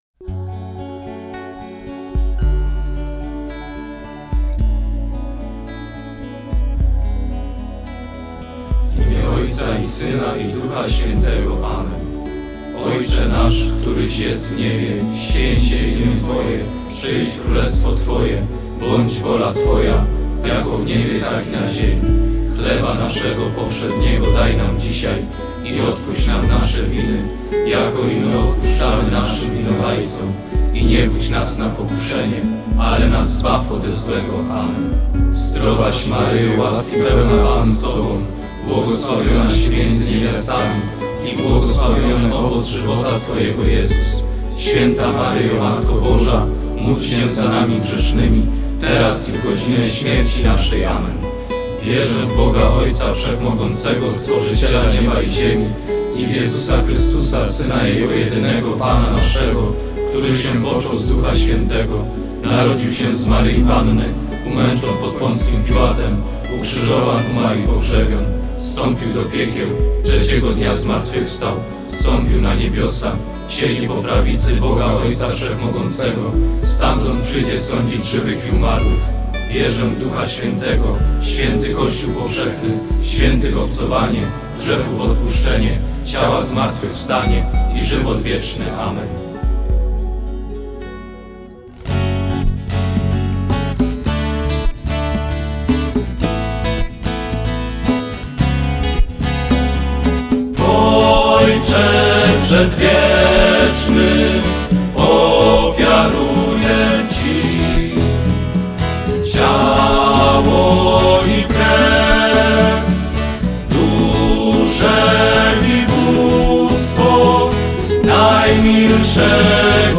POBIERZ Koronkę do Miłosierdzia Bożego w wersji śpiewanej
U progu wakacji weszliśmy do studia Radia eM, żeby nagrać wybrane przez Was w sondzie modlitwy.